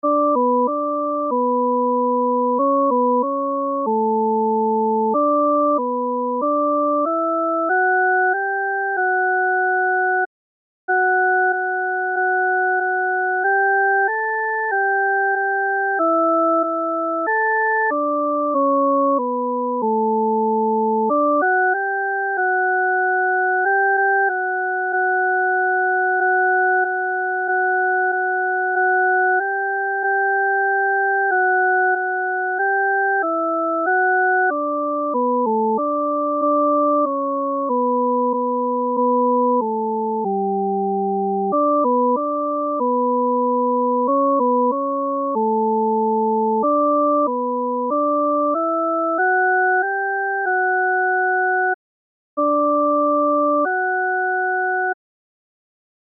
伴奏
女低